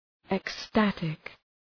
Προφορά
{ek’stætık}